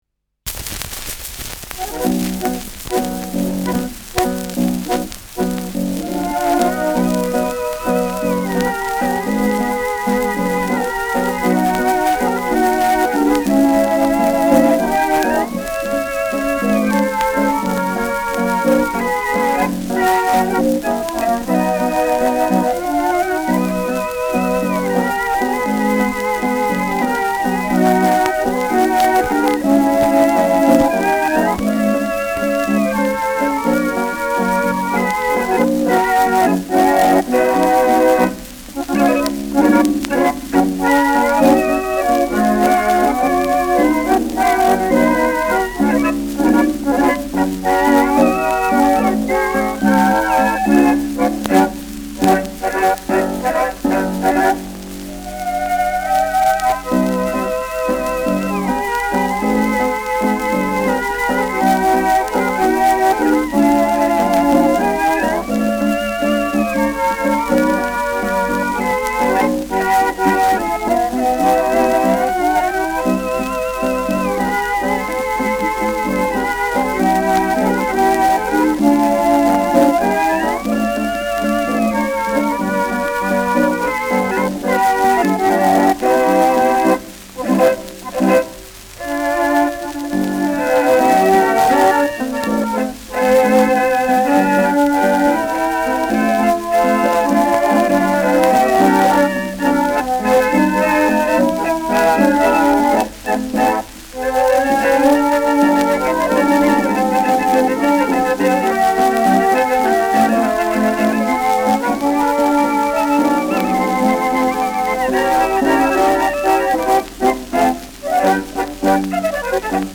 Schellackplatte
präsentes Knistern : leichtes Rauschen : leiert : abgespielt
Schrammel-Trio Schmid, München (Interpretation)
Operettenmelodie* FVS-00011